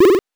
powerup_27.wav